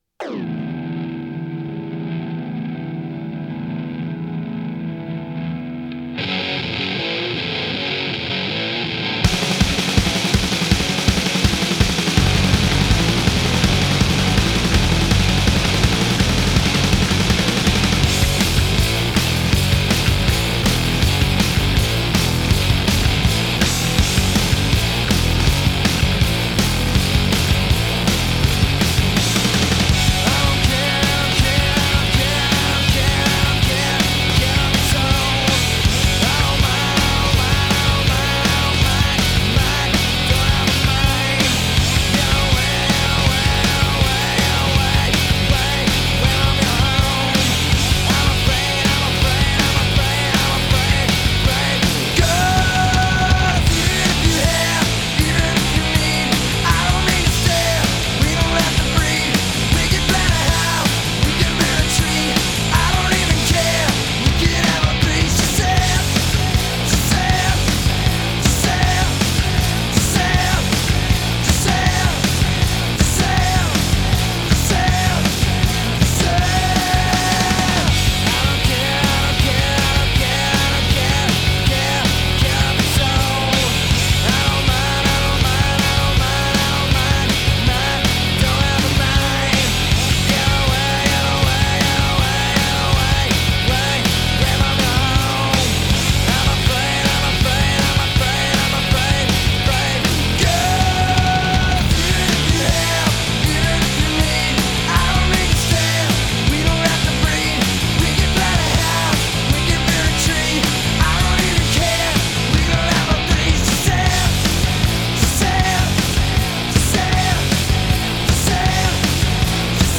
Grunge Гранж